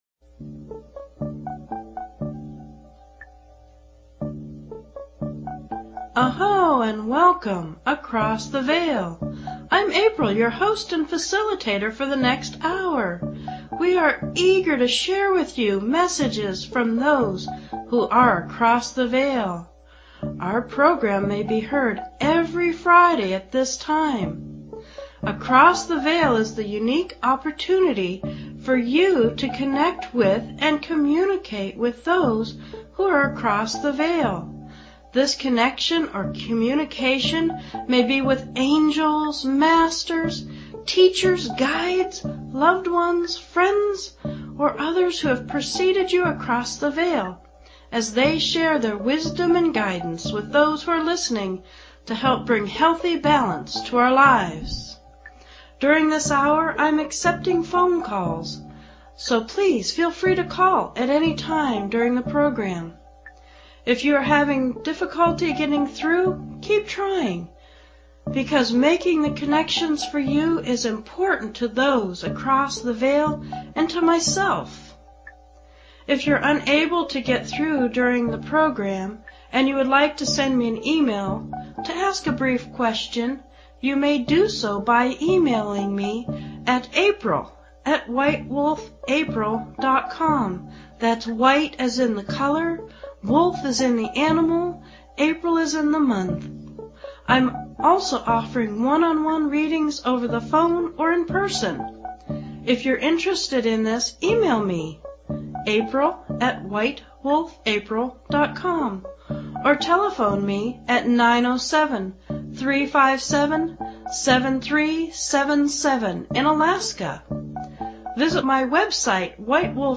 Talk Show Episode
FREE Intuitive Readings Every Week, Every Show, For Every Call-In Across the Veil Please consider subscribing to this talk show.